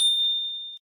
sfx_surprised.ogg